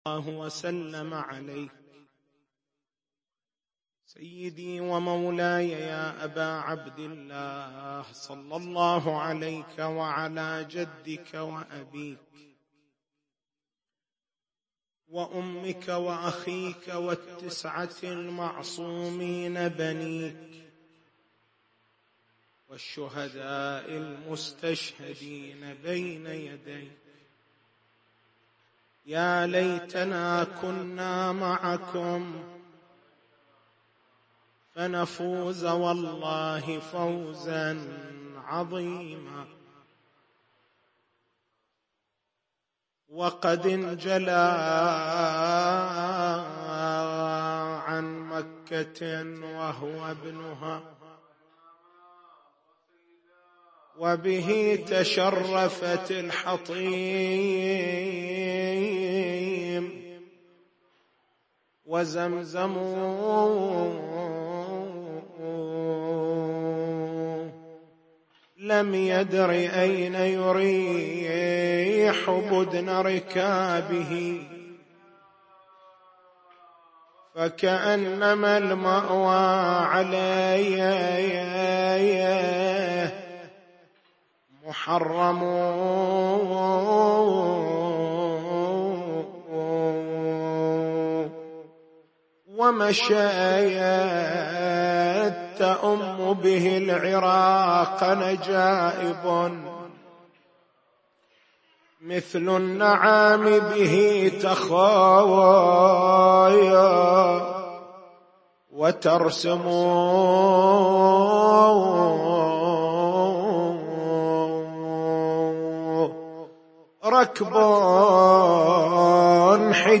تاريخ المحاضرة: 17/01/1439 نقاط البحث: لماذا خرج الإمام الحسين (ع) من المدينة إلى مكّة ولم يتوجّه مباشرة إلى كربلاء؟